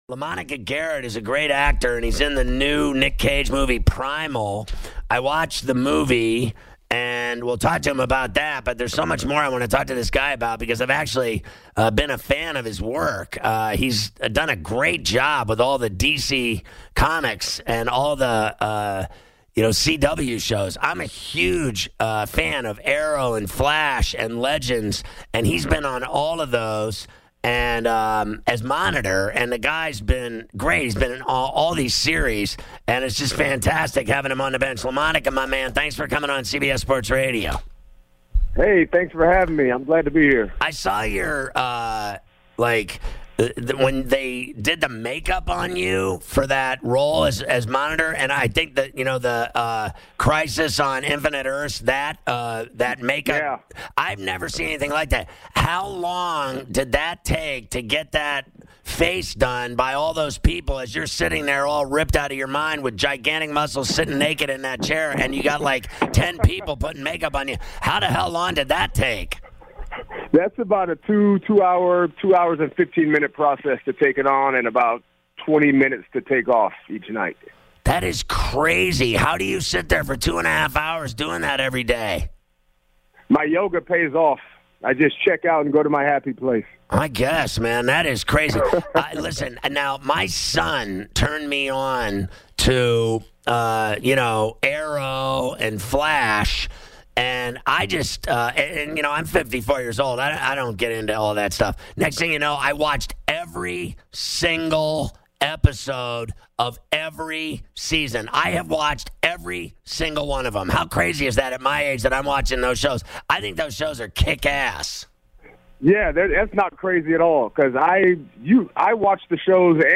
Actor LaMonica Garrett joins Ferrall on the Bench to talk about his career and his new movie, "Primal"
11-07-19- Ferrall on the Bench- LaMonica Garrett Interview